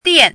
chinese-voice - 汉字语音库
dian4.mp3